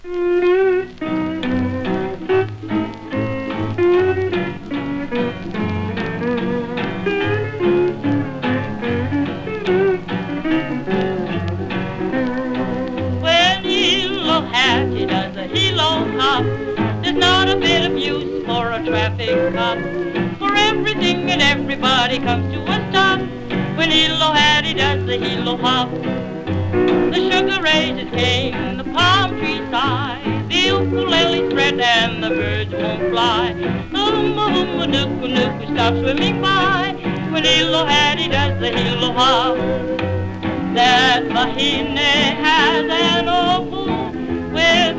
World, Hawaii　USA　12inchレコード　33rpm　Mono